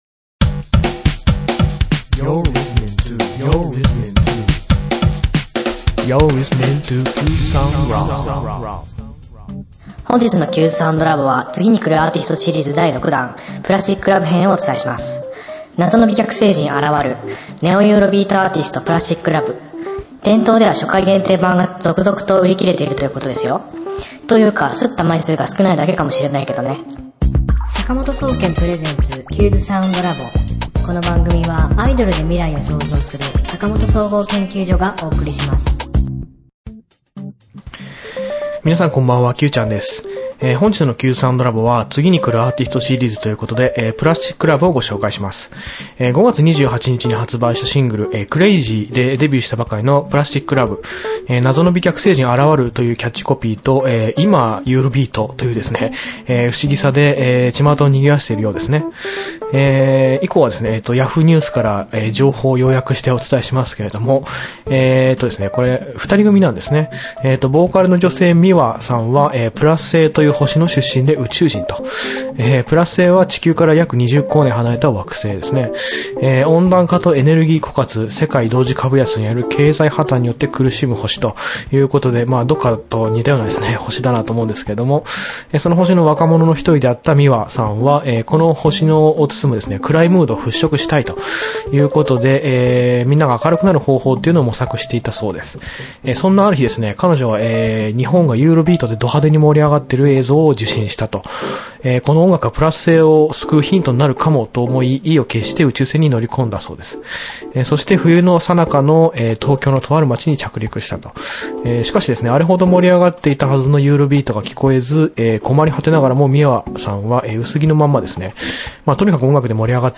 今週の挿入歌